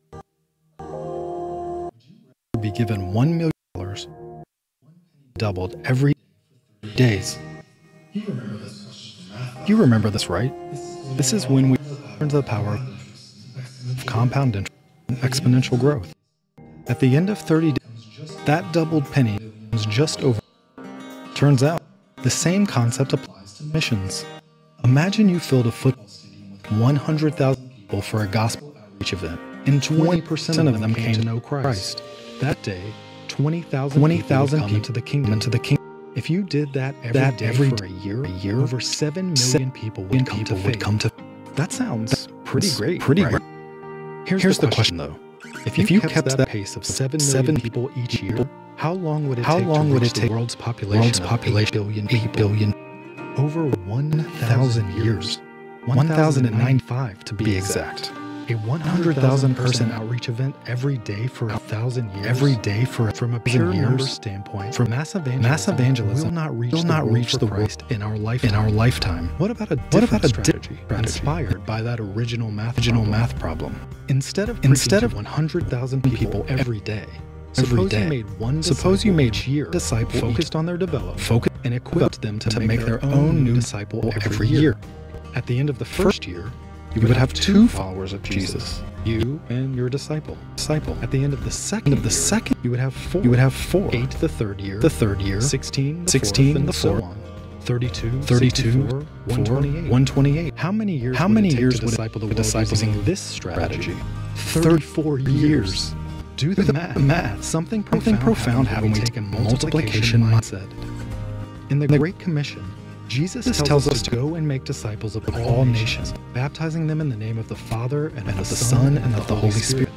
keyboard_arrow_left Sermons / Acts Series Download MP3